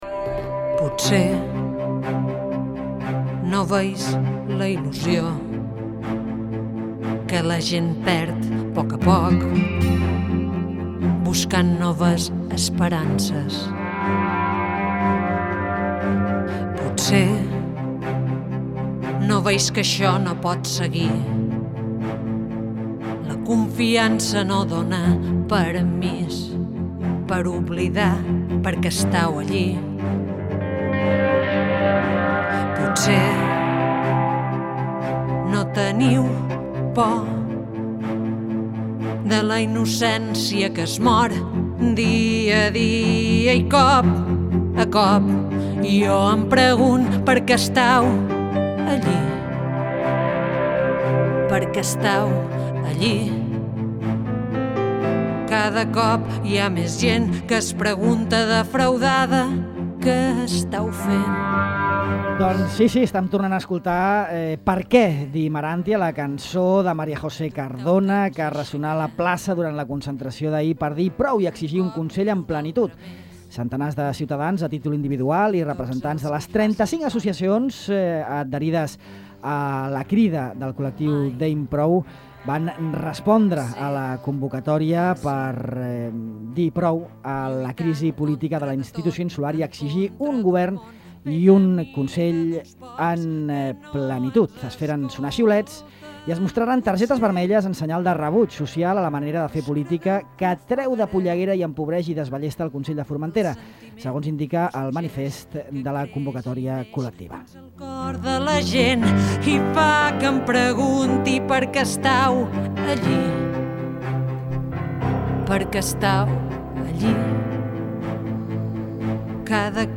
Les portaveus del col·lectiu ‘Deim Prou’